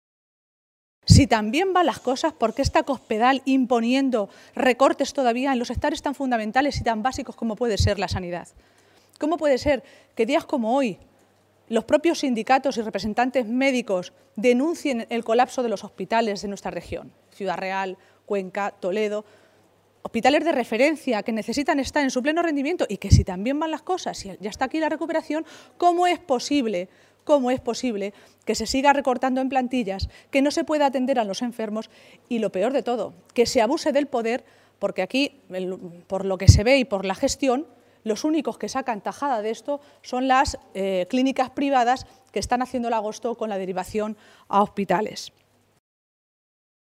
Maestre se pronunciaba de esta manera esta tarde, en una comparecencia ante los medios de comunicación minutos antes de que se reuniera en Toledo la dirección regional socialista.